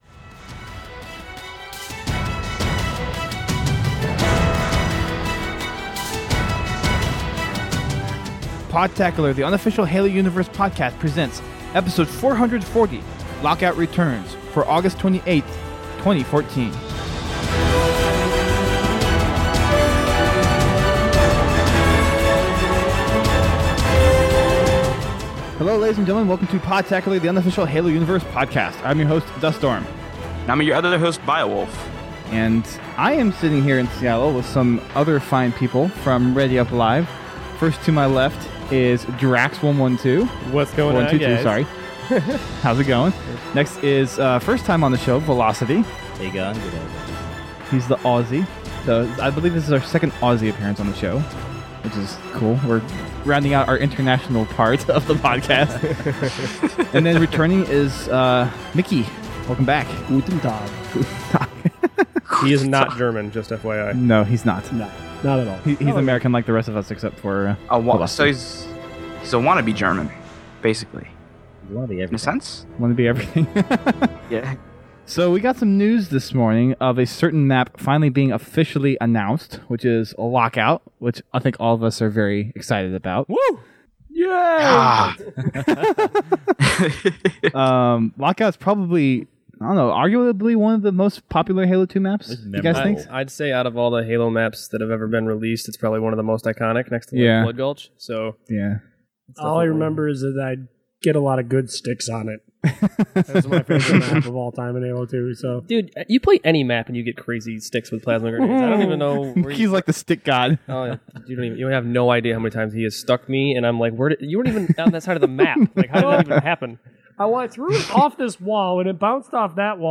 Come hear our take on the news as we record from Seattle, a day before getting our hands on the map at Pax Prime. Halo: Reach will be available under the Games with Gold program next month for Xbox 360.